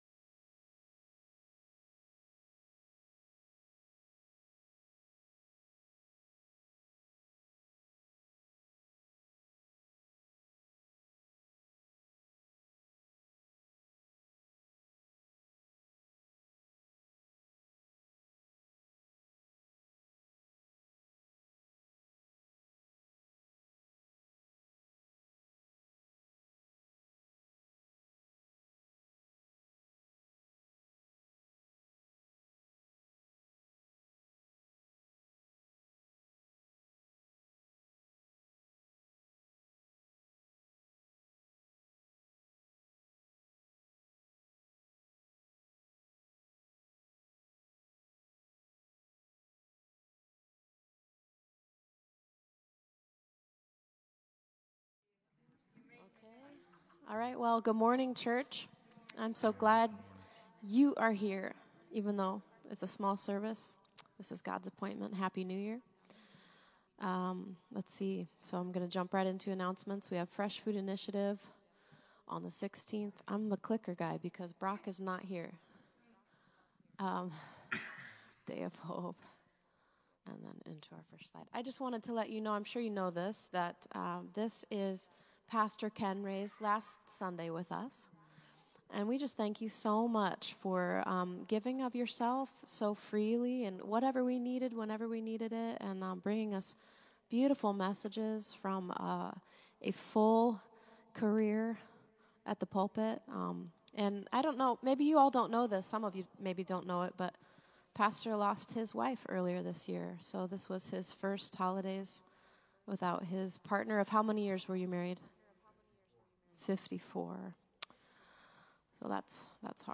Praise Worship
Announcements
Prayer Requests